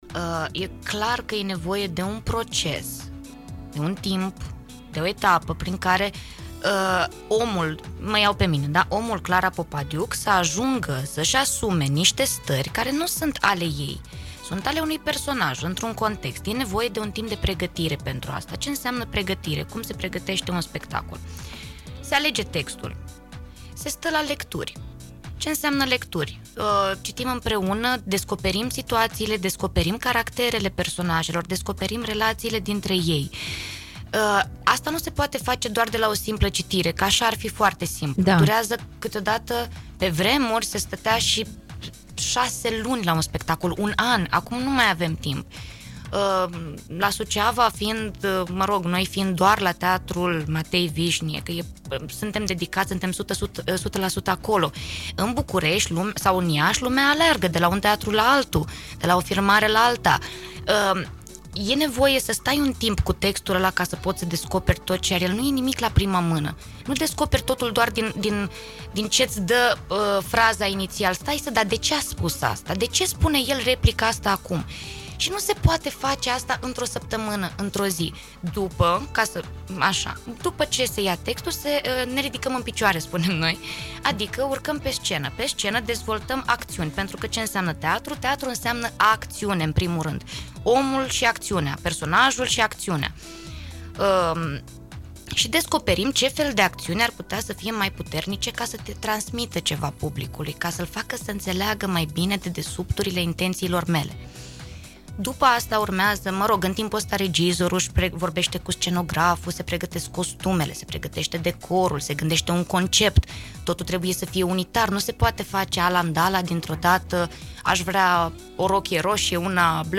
PREGATIREA-UNEI-PIESE-DE-TEATRU.mp3